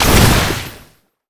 water_blast_projectile_spell_02.wav